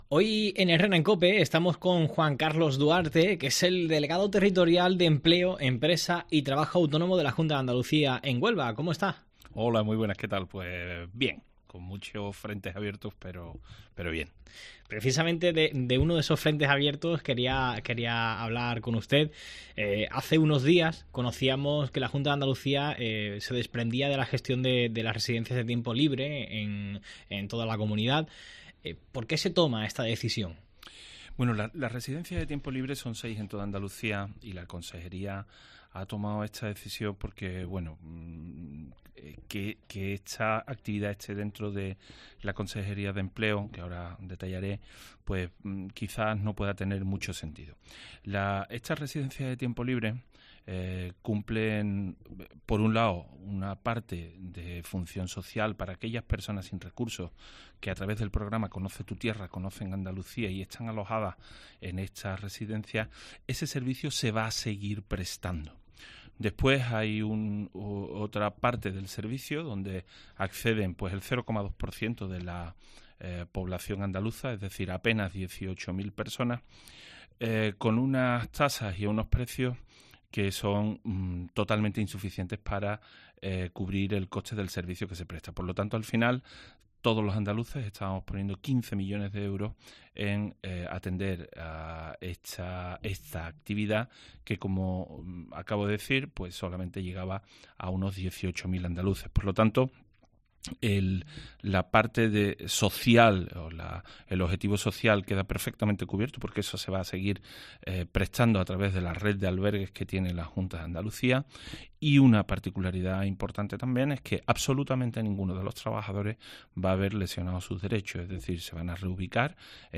El delegado de Empleo en la provincia de Huelva, Juan Carlos Duarte, aborda en COPE asuntos de actualidad, como el futuro de los trabajadores de la residencia de tiempo libre.